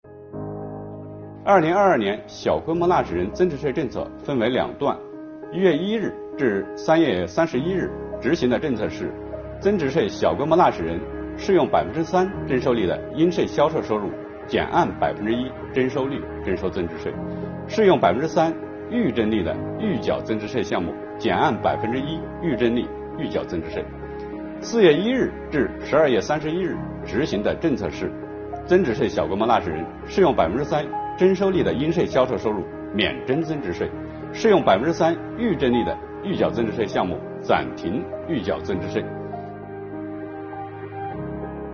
本期课程由国家税务总局货物和劳务税司副司长刘运毛担任主讲人，对小规模纳税人免征增值税政策进行详细讲解，方便广大纳税人进一步了解掌握相关政策和管理服务措施。